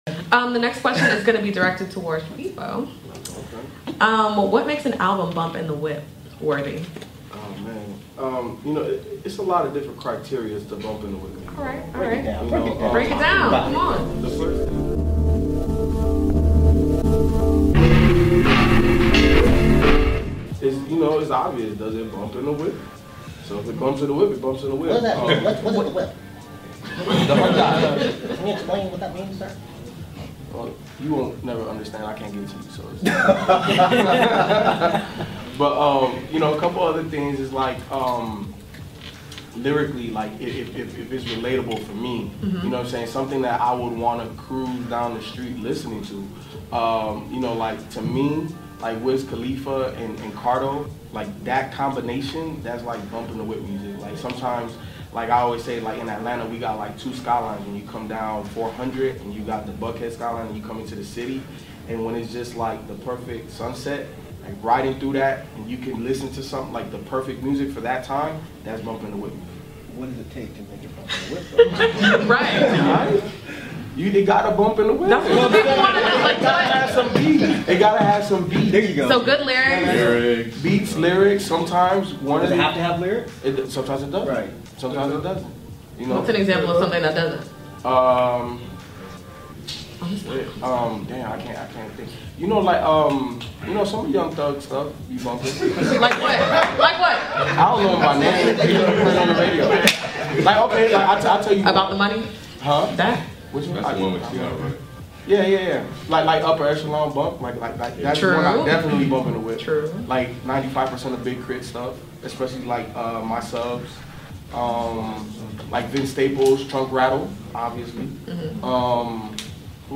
Inside the Mind of a Music Critic Pt.2 | DEHH Live Panel Discussion